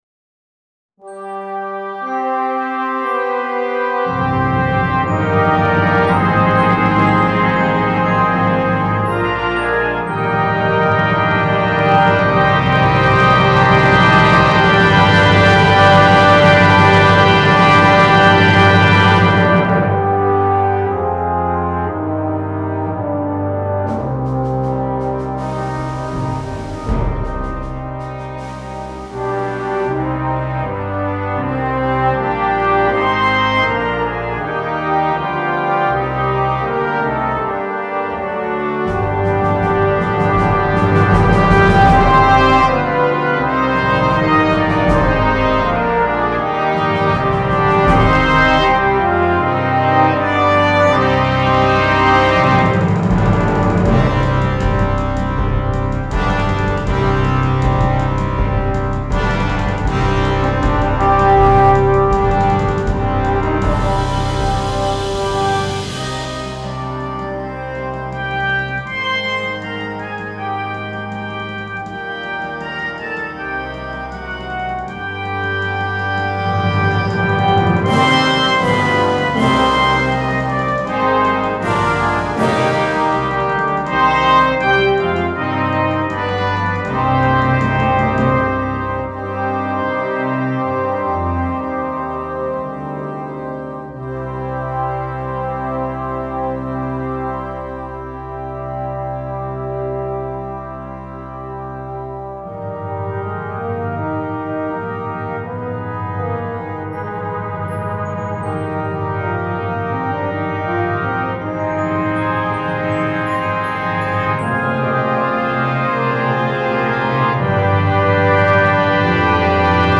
2. Brass Band
Full Band
without solo instrument
Anthem